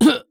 CK被击倒01.wav
CK被击倒01.wav 0:00.00 0:00.31 CK被击倒01.wav WAV · 26 KB · 單聲道 (1ch) 下载文件 本站所有音效均采用 CC0 授权 ，可免费用于商业与个人项目，无需署名。
人声采集素材/男2刺客型/CK被击倒01.wav